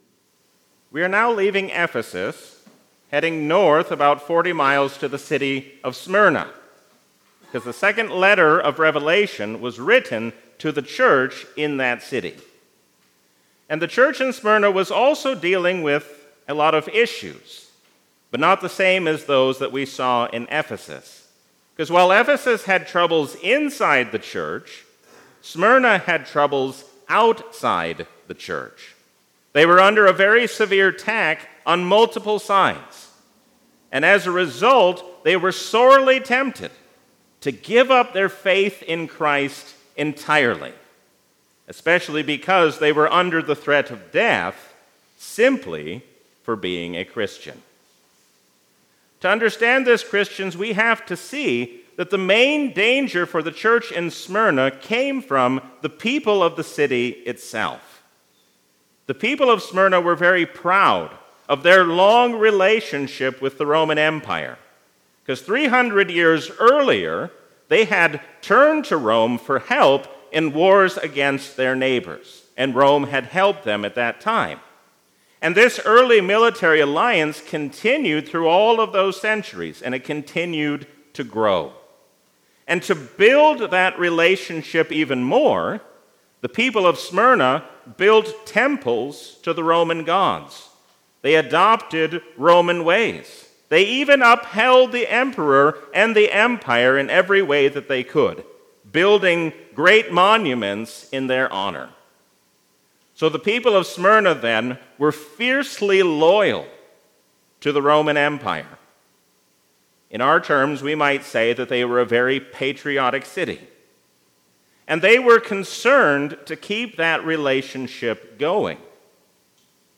A sermon from the season "Easter 2023."